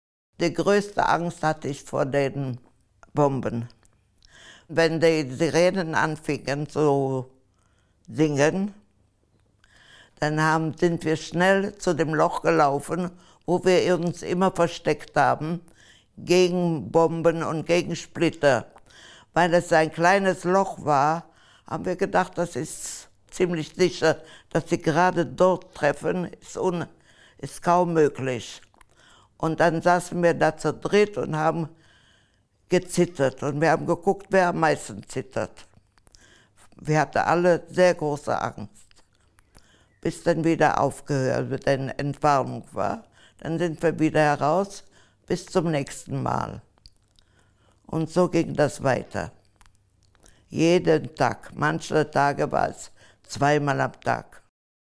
Zeitzeugeninterview